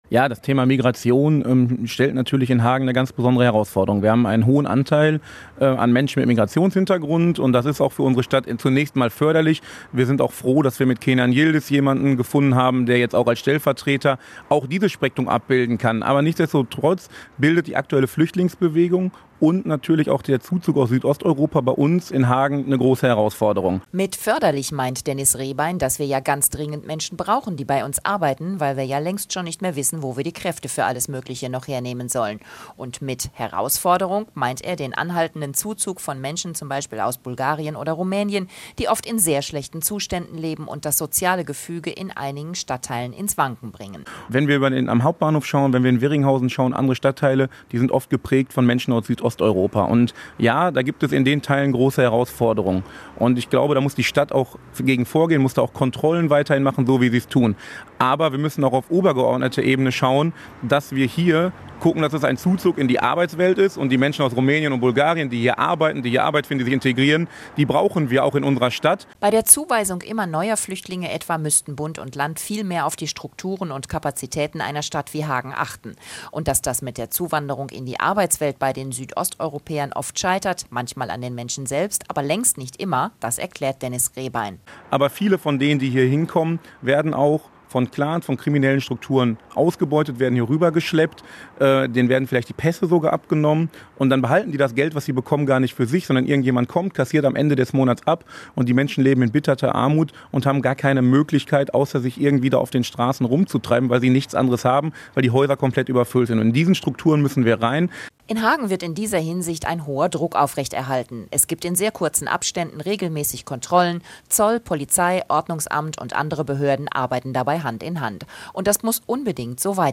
am Rande des CDU-Kreisparteitages am Samstag